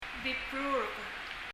bibrurk[bibru(:)rk]黄色yellow